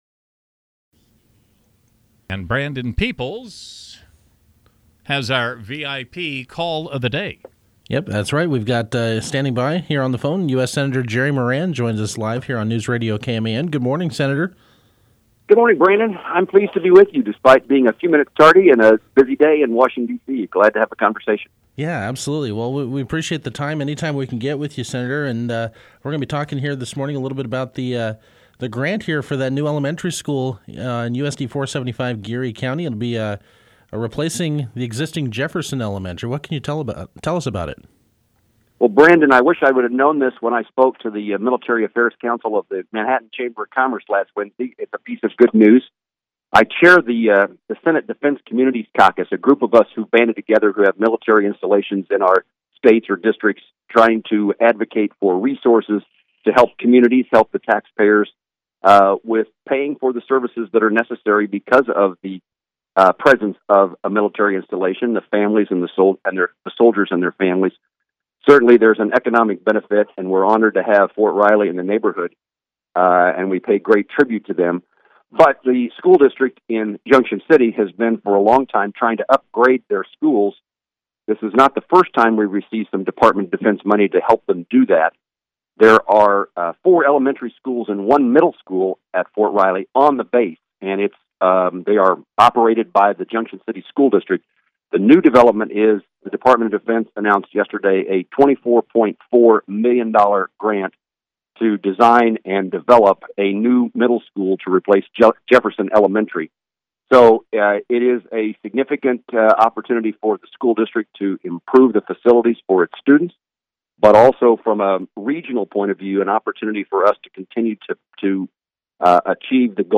U.S. Senator Jerry Moran live on KMAN Morning Show